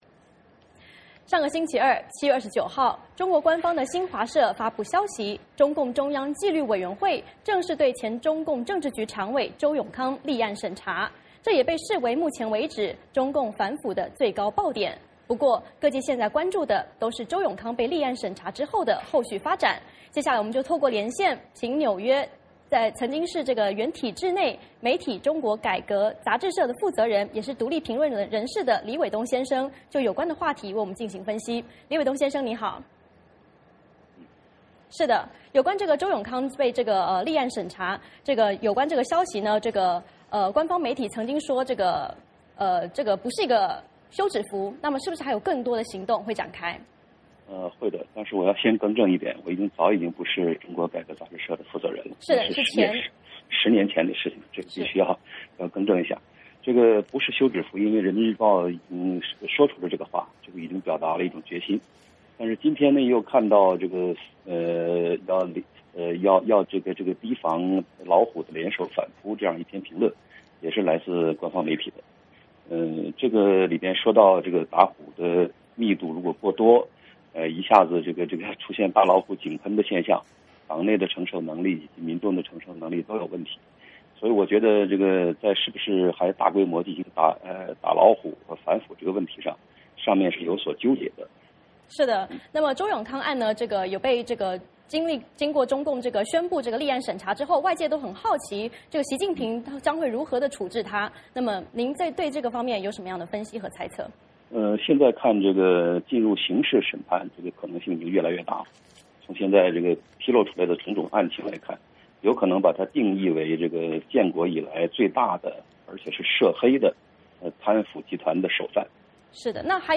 VOA连线：专家分析：周永康被立案审查的后续发展